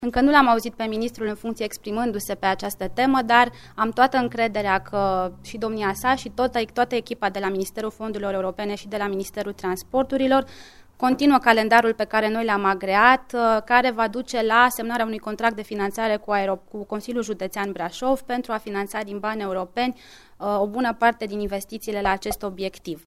Fostul ministru al Fondurilor Europene, Roxana Mînzatu: